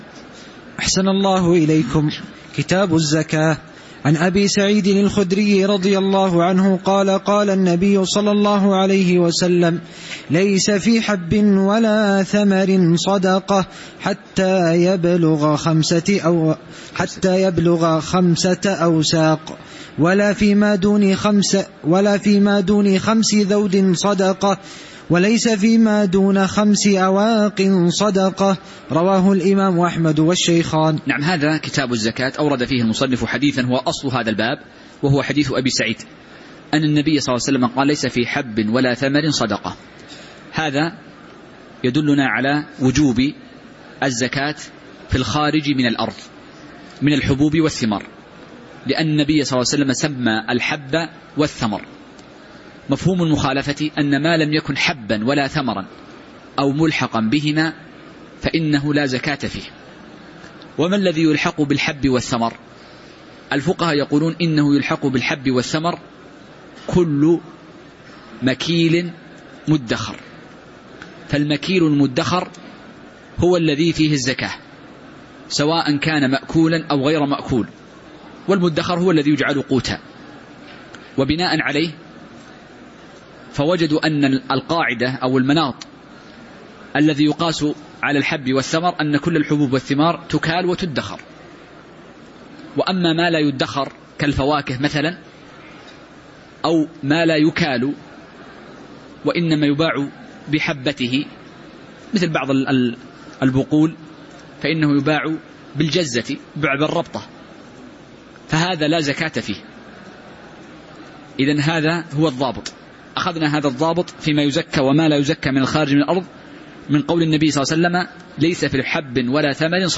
تاريخ النشر ٢٥ جمادى الآخرة ١٤٤٠ هـ المكان: المسجد النبوي الشيخ